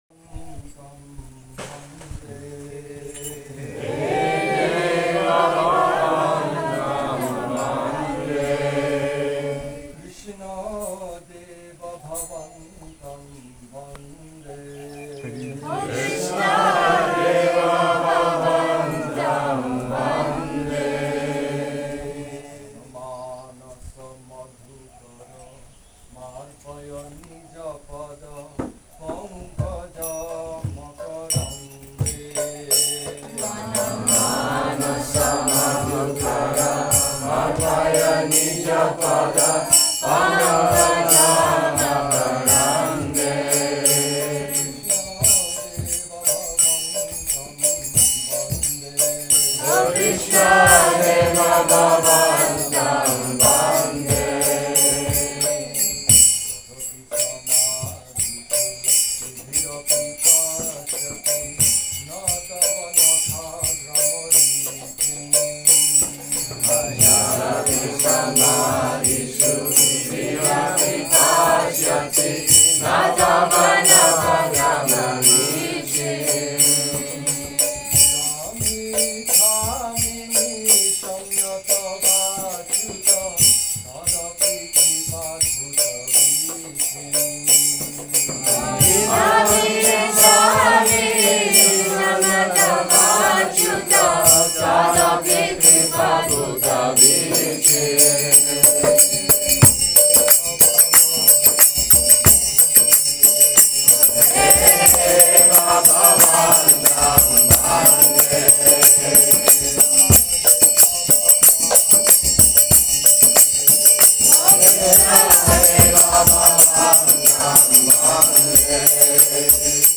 Govinda Farm, Thailand | «Хе Дева бхавантам ванде».